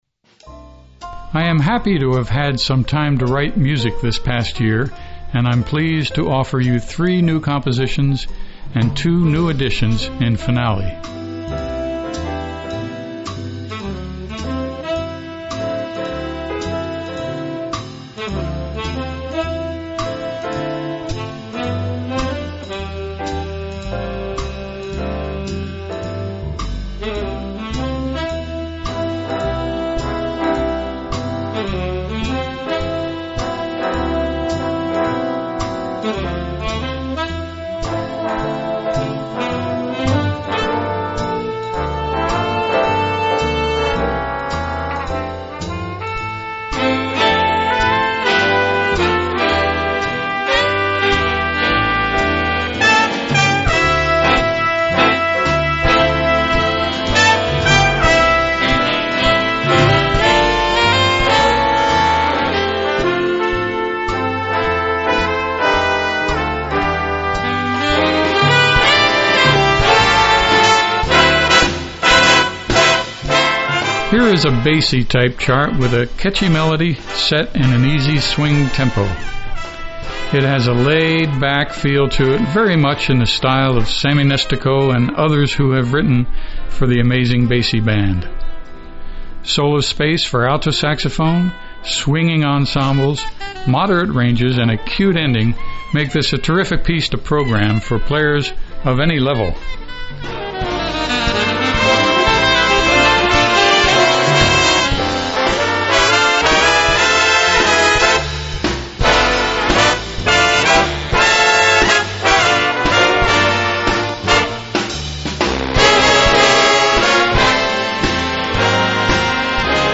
with a catchy melody set in an easy-swing tempo
a laid-back, swinging chart